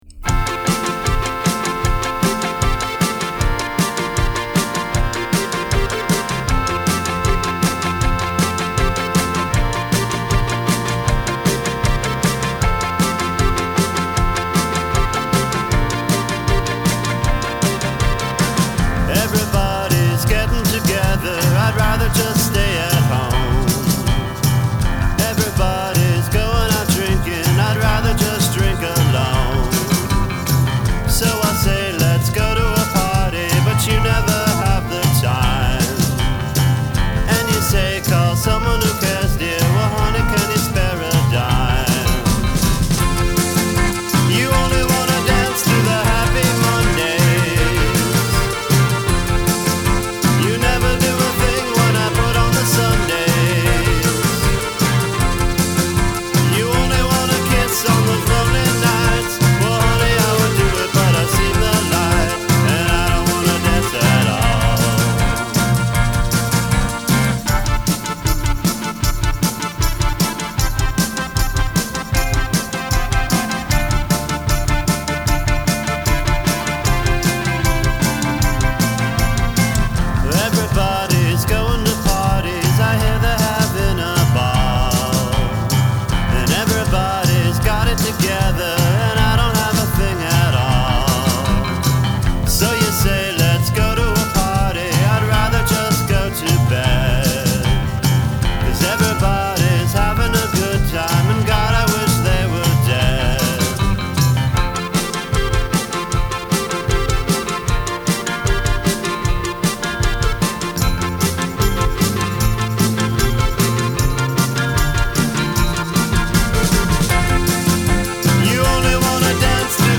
drums
synthesizer
bass